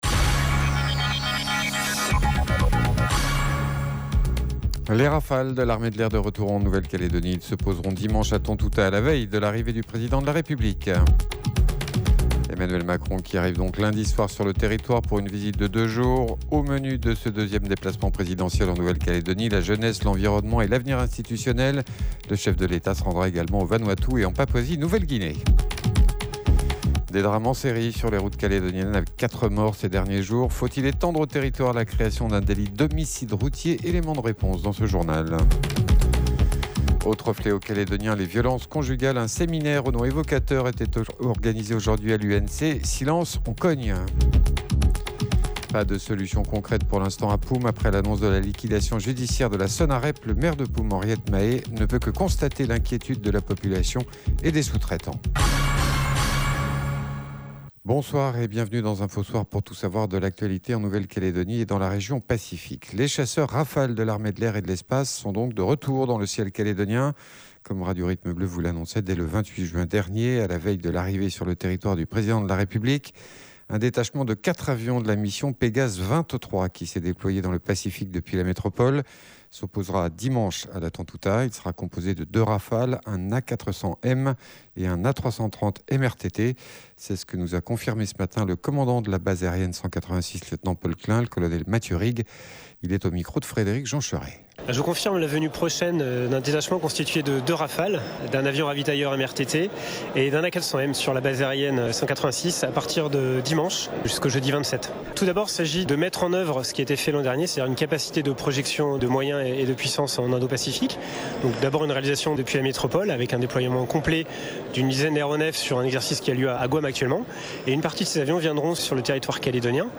JOURNAL : INFO SOIR MARDI